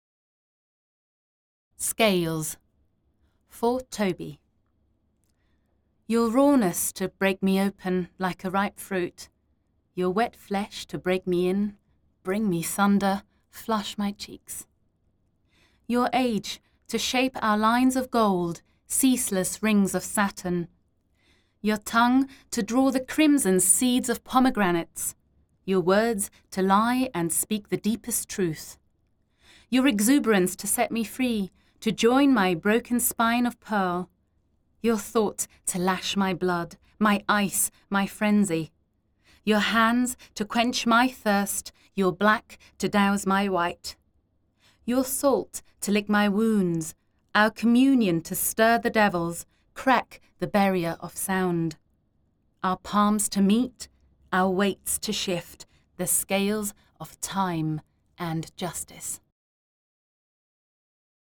Recorded at DoubleDouble Creative & Productions, Hong Kong